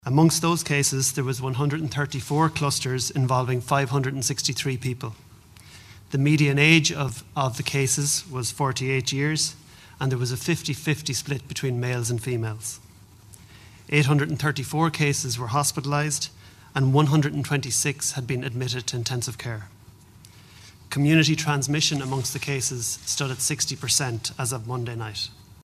The latest figures have been announced at a press briefing this evening.